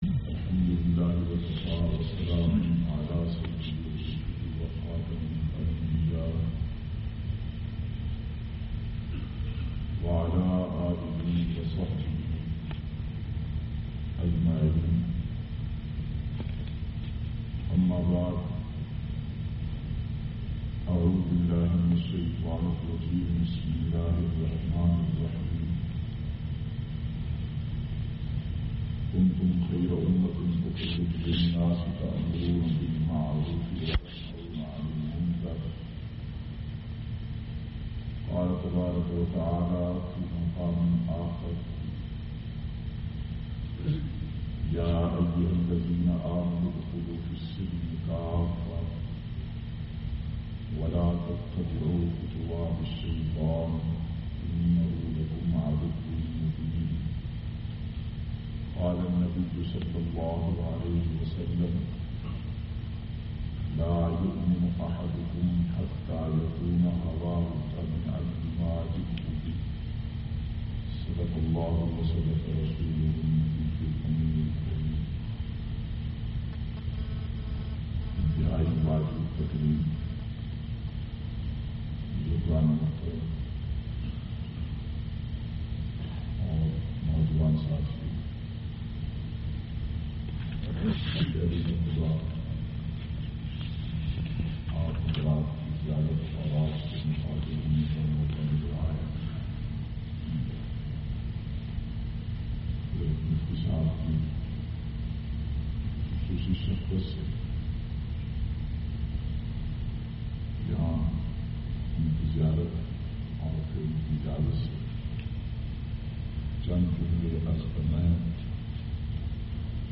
668- Islah e Muashira Khutba Jumma Glassgo UK.mp3